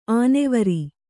♪ ānevari